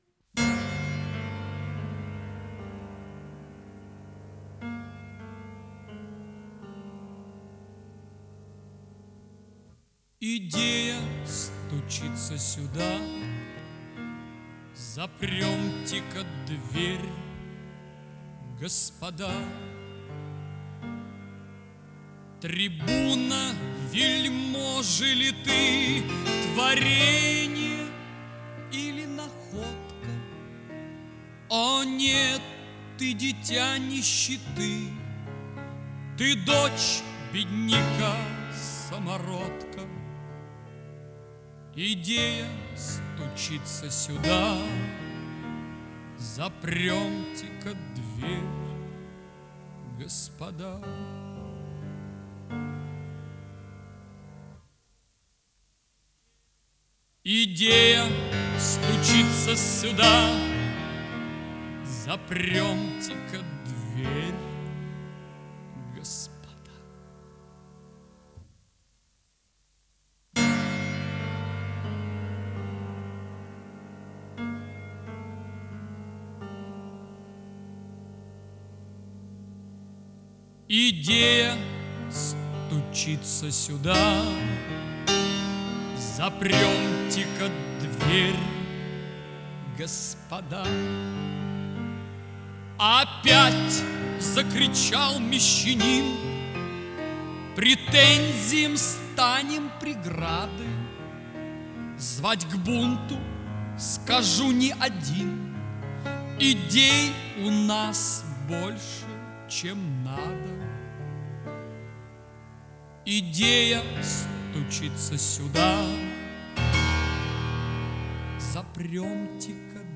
Жанр: Rock, Pop
Стиль: Art Rock, Ballad, Vocal, Classic Rock
Вокальная сюита